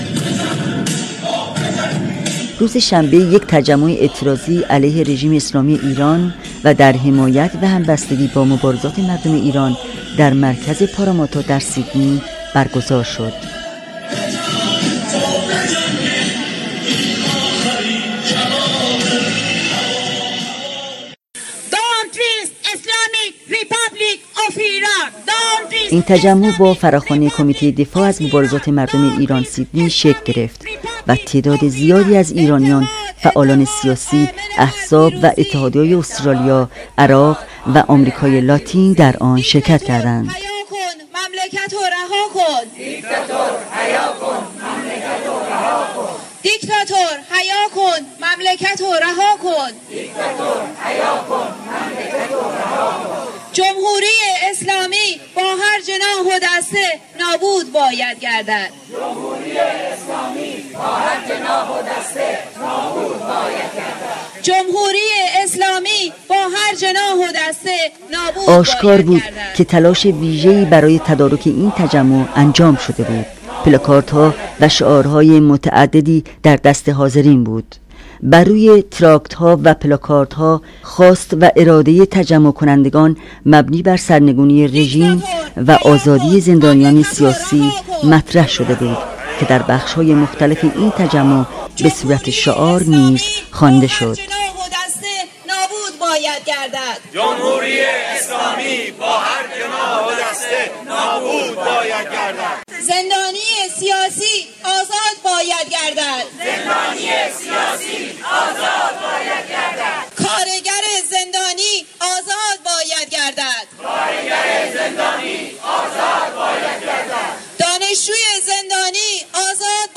Report. Protest in Sydney.mp3